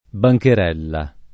bancherella → bancarella
bancherella [ ba j ker $ lla ] → bancarella